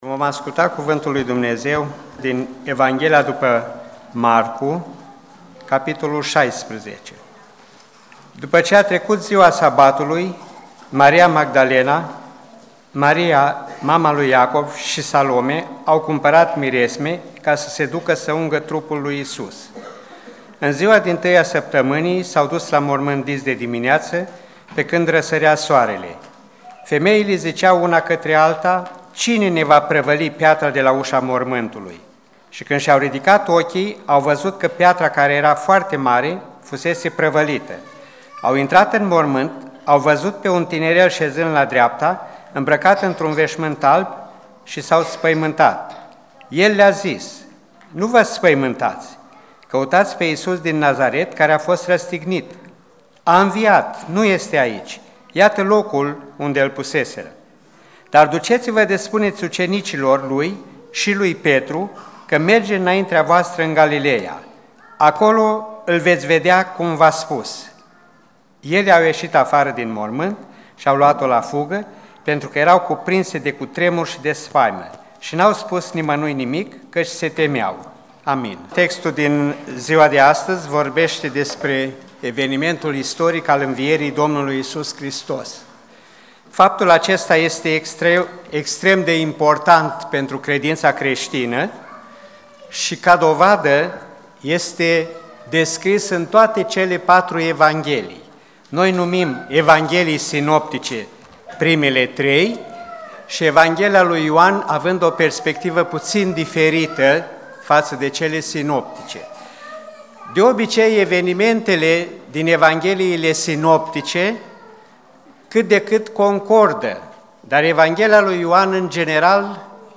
Mesajul a fost înregistrat în biserica Filadelfia din Suceava.